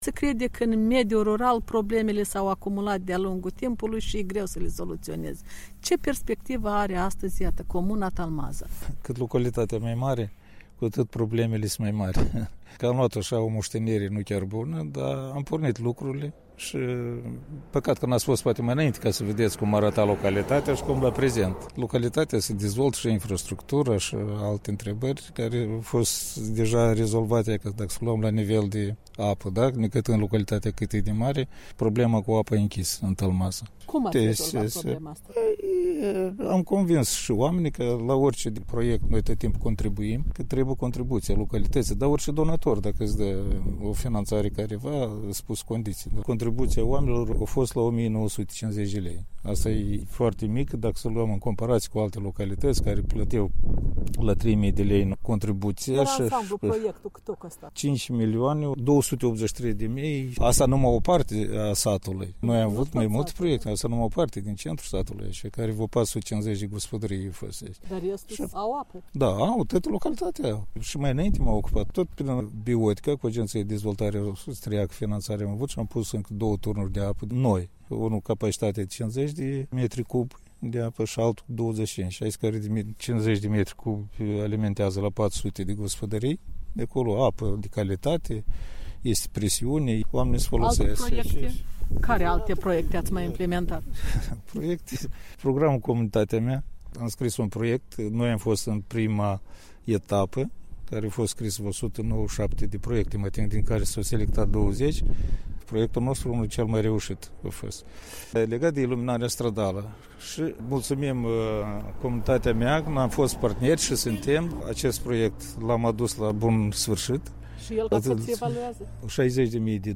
O convorbire cu Eugeniu Pruteanu, aflat la al doilea mandat de primar în comuna Talmaza, raionul Ștefan Vodă.